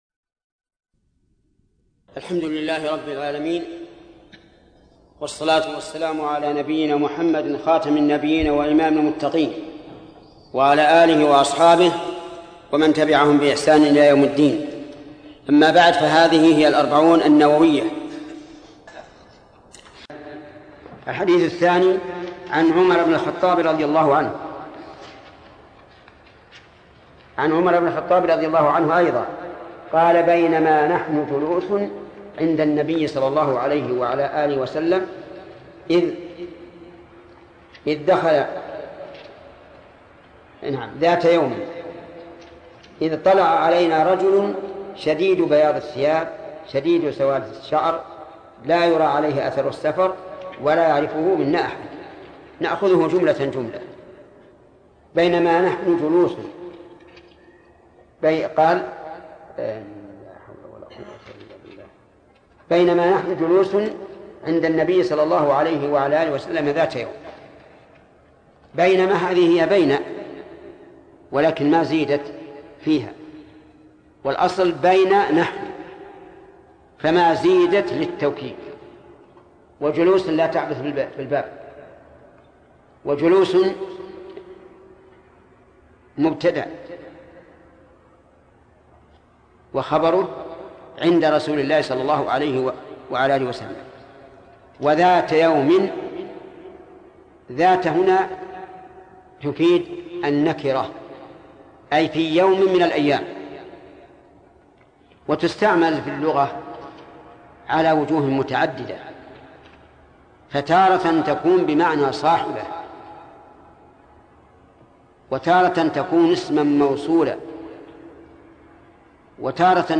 الدرس الثاني: من : الحديث الثاني، إلى قوله: "وتصوم رمضان".